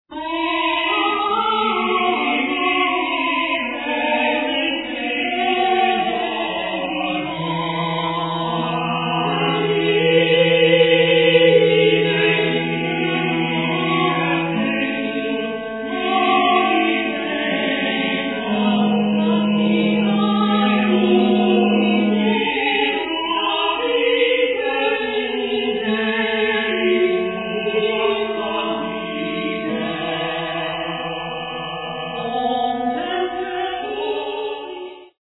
tenor
Baritone
organist